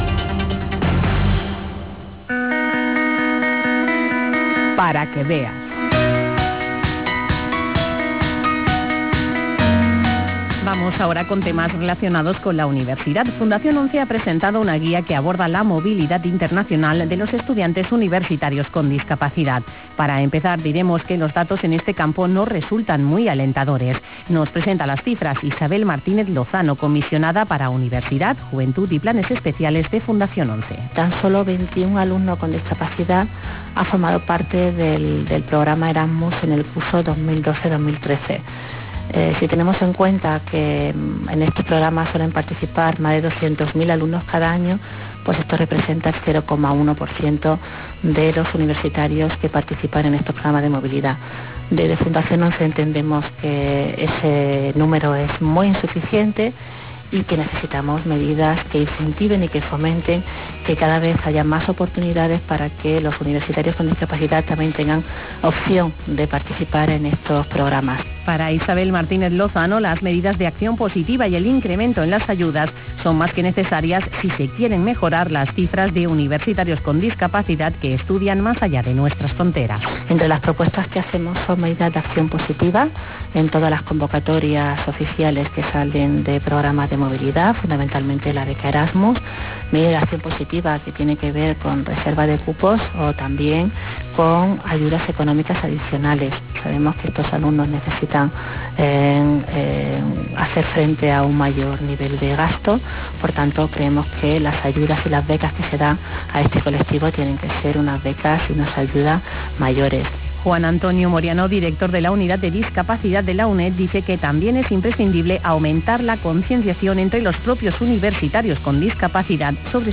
Reportaje de la Presentación de la guía de Fundación ONCE sobre la movilidad de los estudiantes con discapacidad.
guia_estudiantes_con_discapacidad_-_radio_5.wav